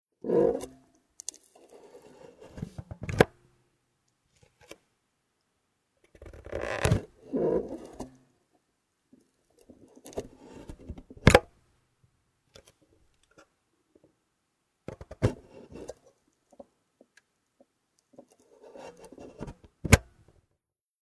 Opening and closing the housing
0156_Gehaeuse_oeffnen_und_schliessen.mp3